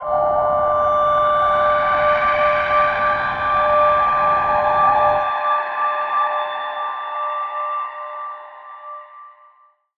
G_Crystal-D7-mf.wav